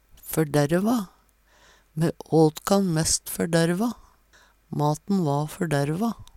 førderva - Numedalsmål (en-US)